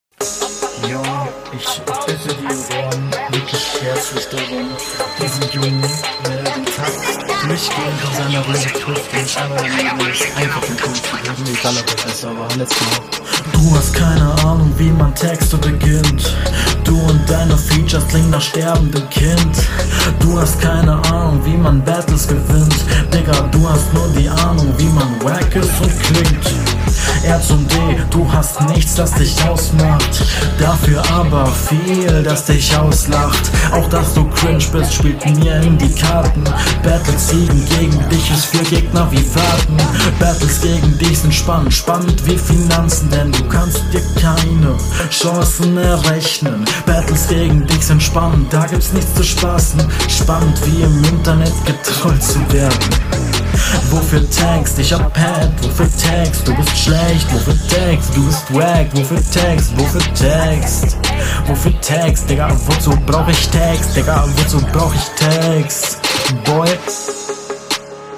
Diese Runde von dir hat zu wenig Druck, leider.
Beat ist mega.
Bitte etwas mehr Druck in die Stimme.